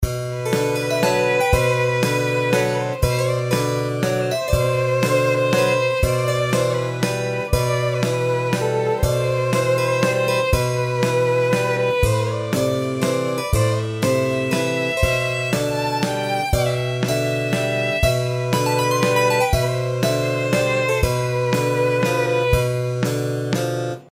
Ich bin inzwischen bei einer größeren Herausforderung angekommen: Um der Unsicherheit und Zerrissenheit Rechnung zu tragen, die aus dem Text spricht (für mich zumindest), wäre ich inzwischen bei Tonart Lokrisch.
Von der Instrumentierung: ein Cembalo für die Grundstimmung, eine Geige für die Melodie.
Gesang: eher rezitierend als gesungen. Takt: 3/4.
Hilft immer mehr als Schweigen Ein völliger Rohentwurf anbei.